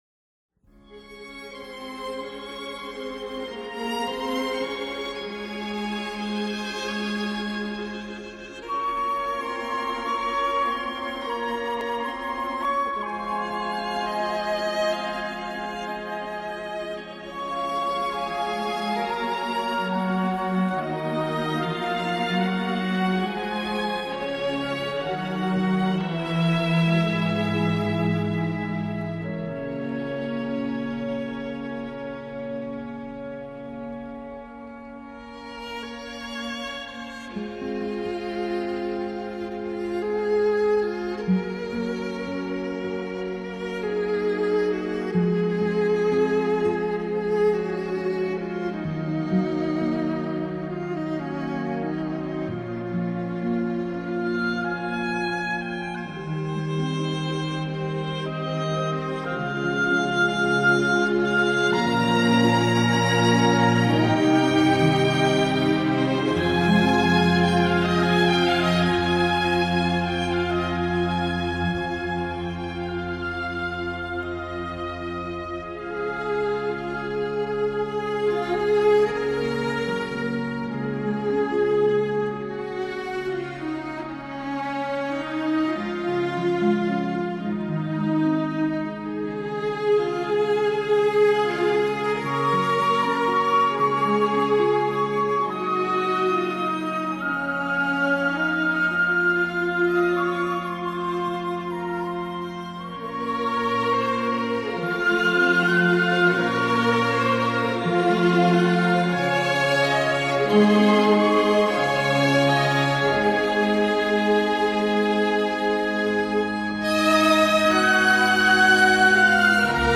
la romántica y conmovedora partitura